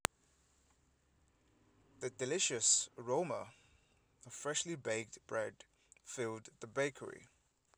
UniqueData/speech-emotion-recognition-dataset at ed7e3915a695f292aa3d621c60f1f68bc3b9d2d1
euphoric.wav